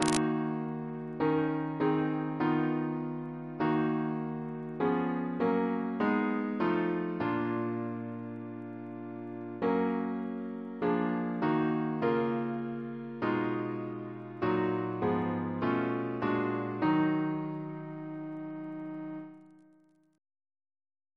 Double chant in E♭ minor Composer: Chris Biemesderfer (b.1958) Note: for Psalm 22